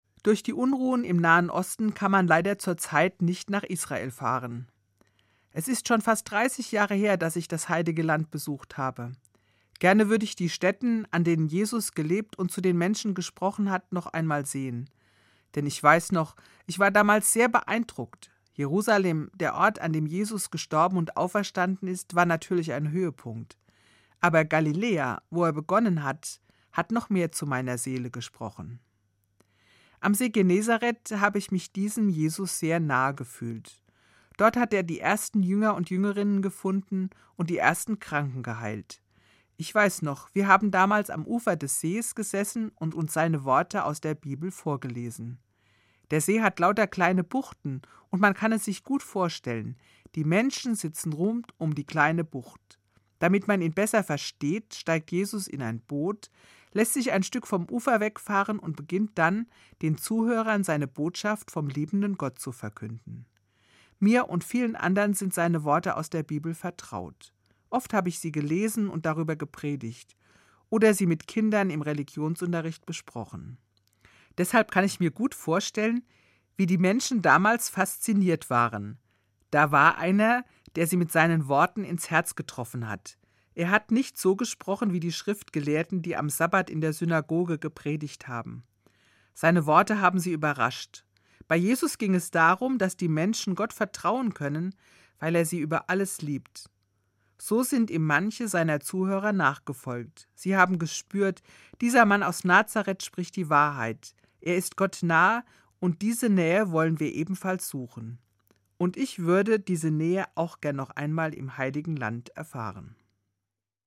Katholische Gemeindereferentin im Ruhestand